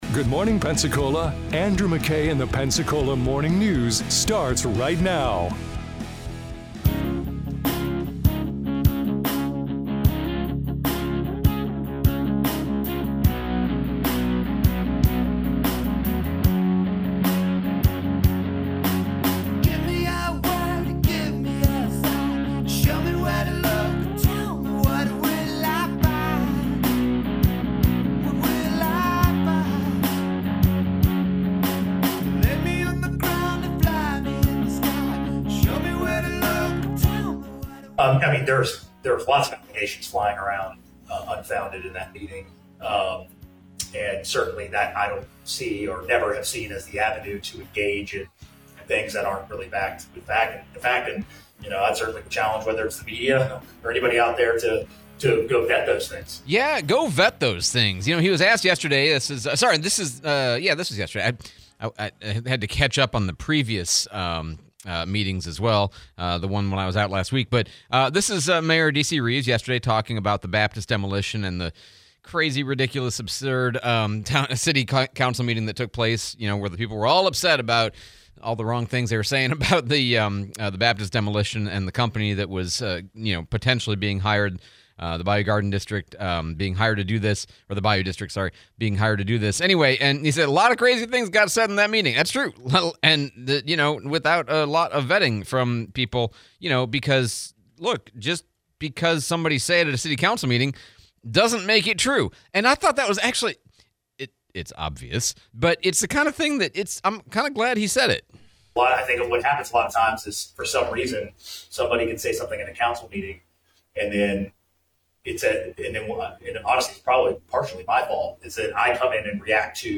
Mayor DC Reeves Press Conference, Replay of Escambia County Sheriff Chip Simmons